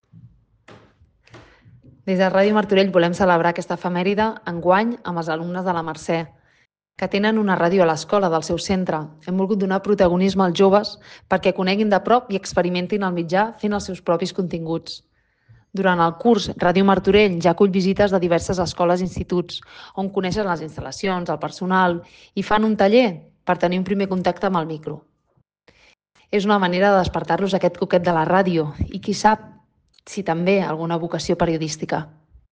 Cristina Dalmau, regidora de Mitjans de Comunicació